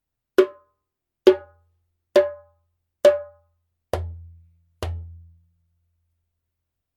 Wood 胴木材 : ゲニ (ハレ カディ バラフォン) Gueni (Hare Khadi Balafon)
Mサイズ規格ですが口径30cmあってゲニ材らしいくっきり明快な音色。
ジャンベ音